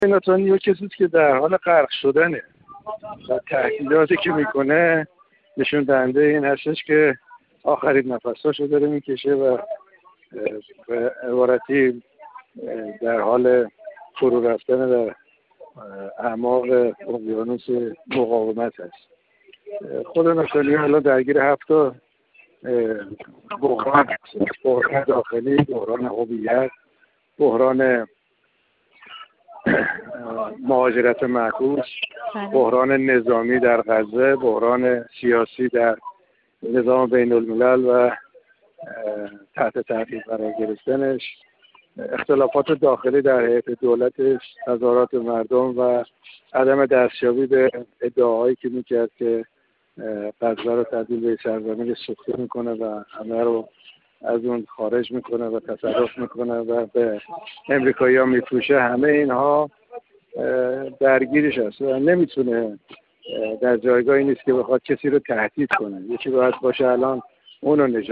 کارشناس مسائل سیاسی
گفت‌وگو